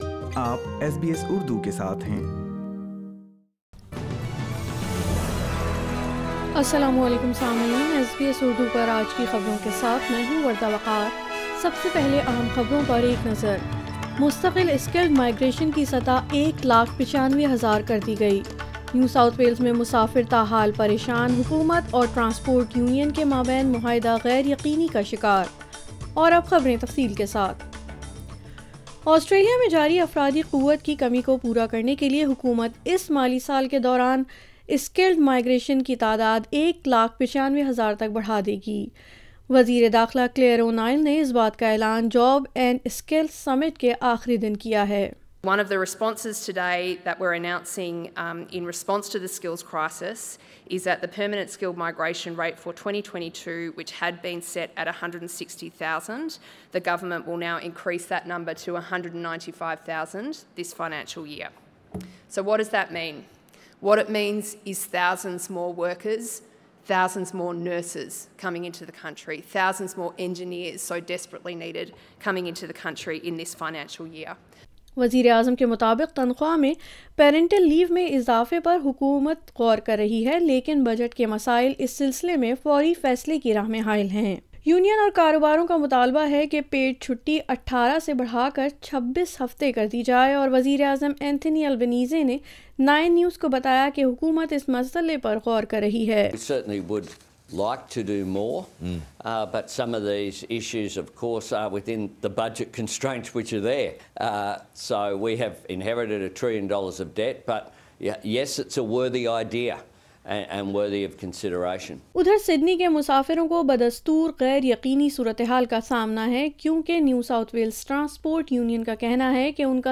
Urdu News 02 September 2022